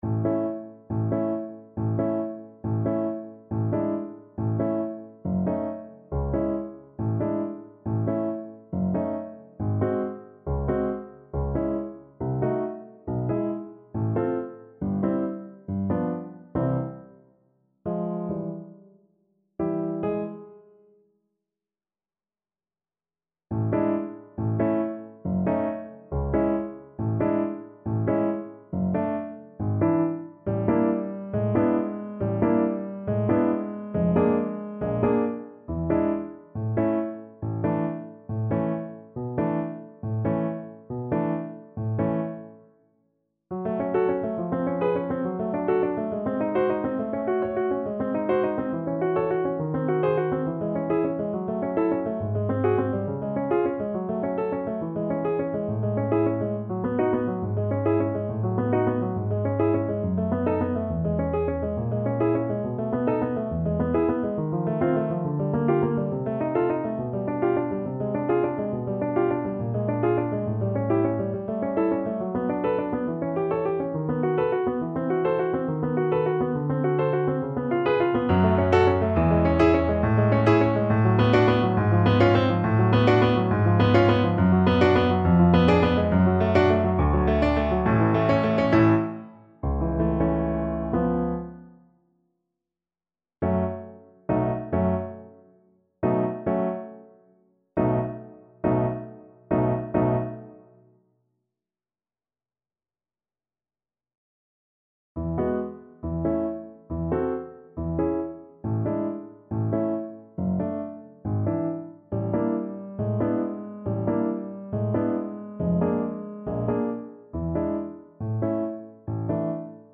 Play (or use space bar on your keyboard) Pause Music Playalong - Piano Accompaniment Playalong Band Accompaniment not yet available reset tempo print settings full screen
A major (Sounding Pitch) (View more A major Music for Viola )
Allegro vivo =138 (View more music marked Allegro)
Classical (View more Classical Viola Music)